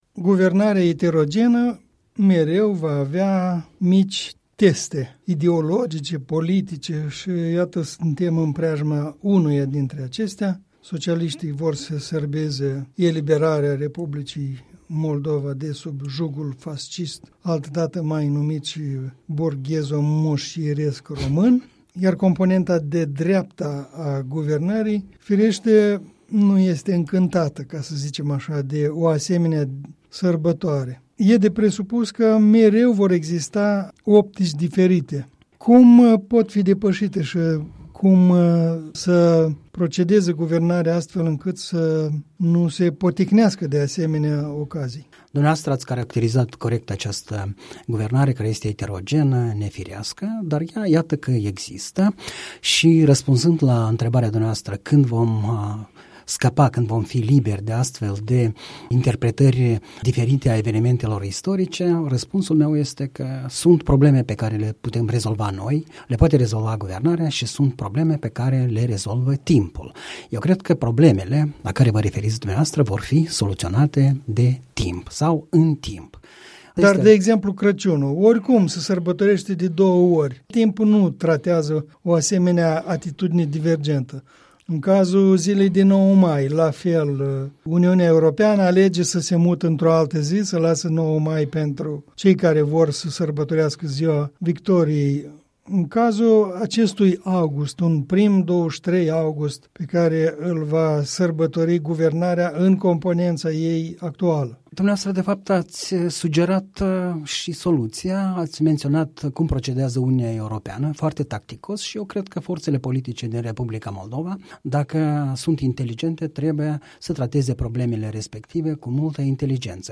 Un comentariu săptămânal în dialog la Europa Liberă.